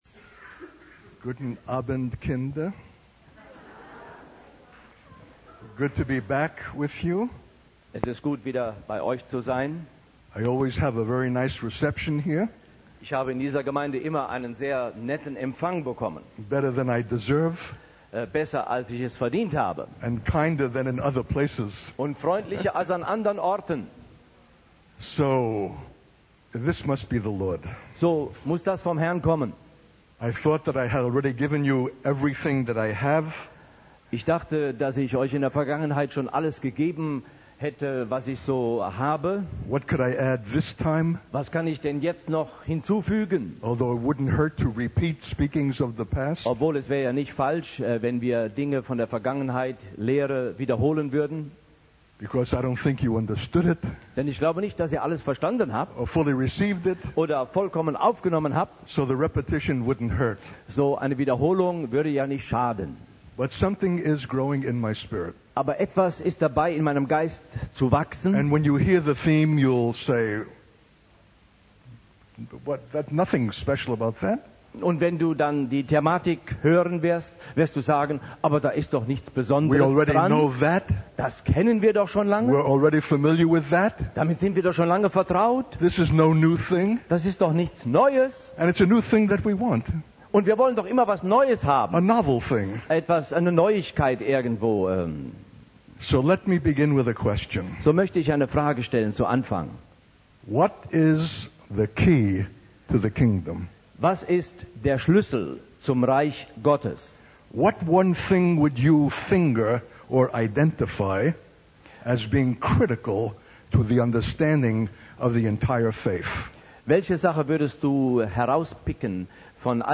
In this sermon, the speaker begins by expressing gratitude for the warm reception he always receives from the audience.